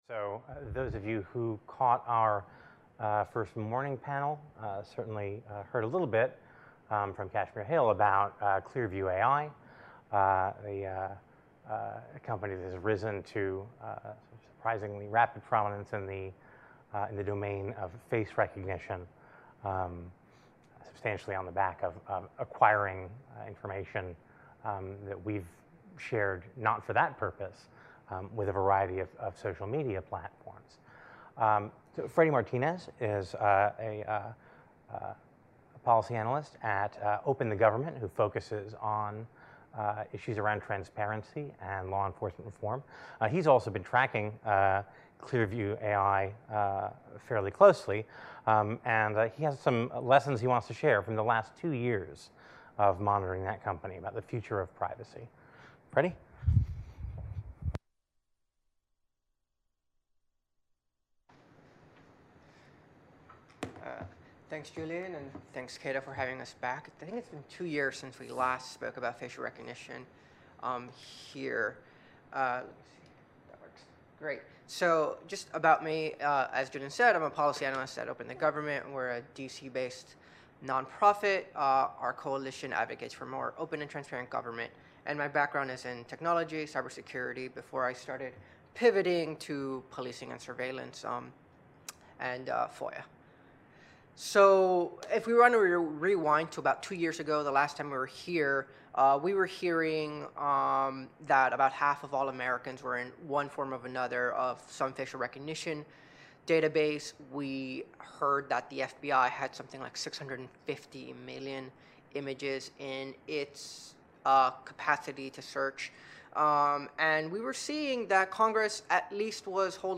Join us live online, streaming from the Cato Institute.